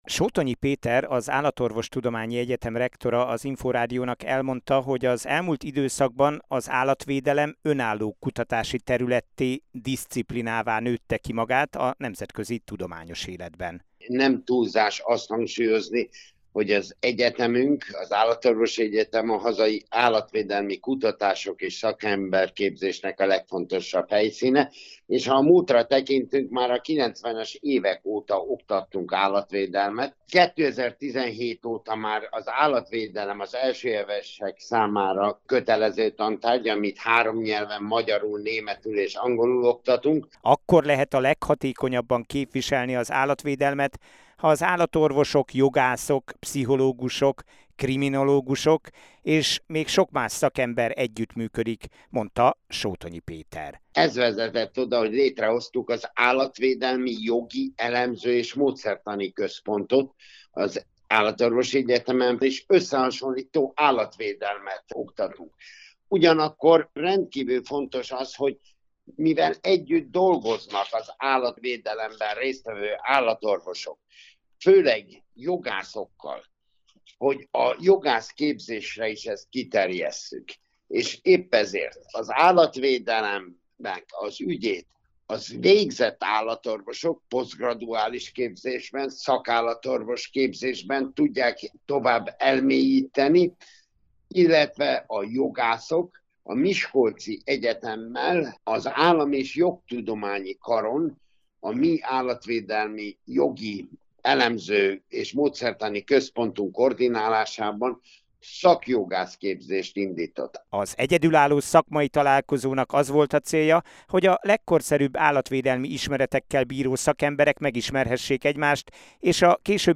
InfoRádió: Interjú